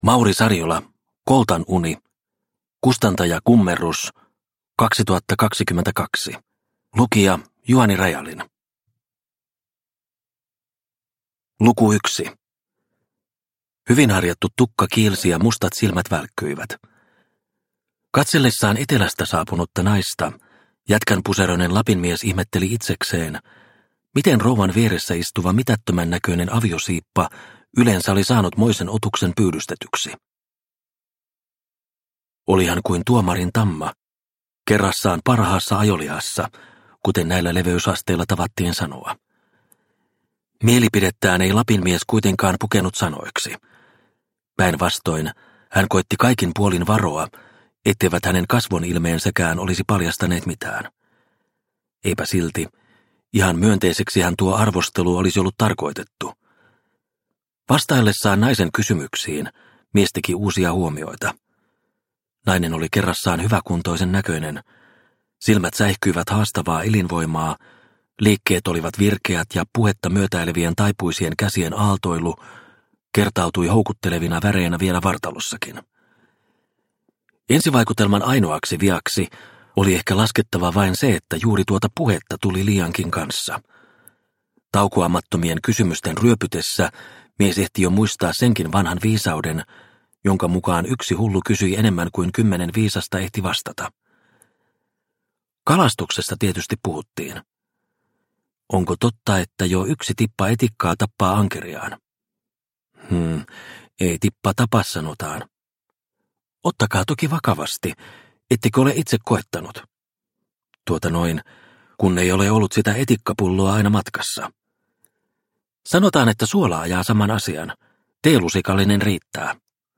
Koltan uni – Ljudbok – Laddas ner